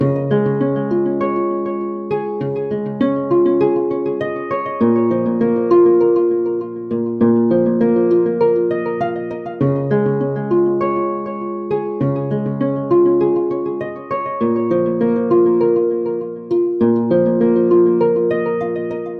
标签： 100 bpm RnB Loops Vocal Loops 3.23 MB wav Key : C Cubase
声道立体声